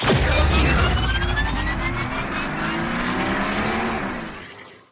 Appear_Scatter_Sound_Final.mp3